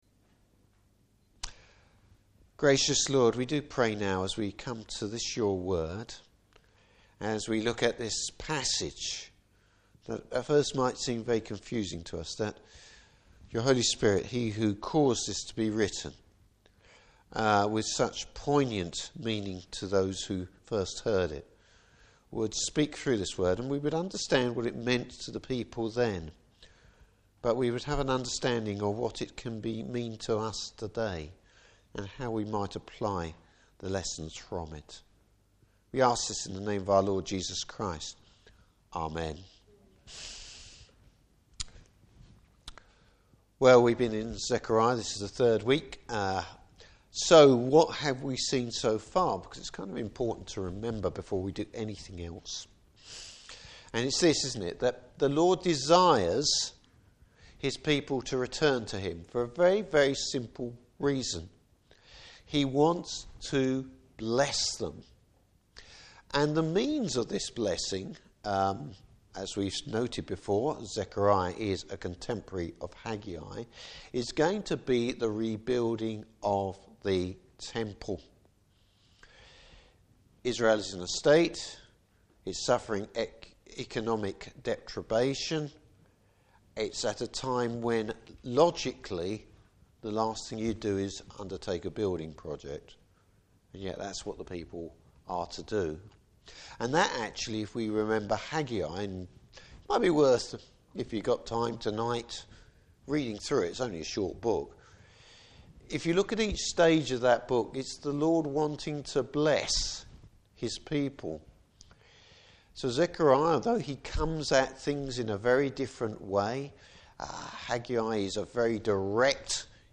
Service Type: Evening Service The Lord’s future blessing for his people.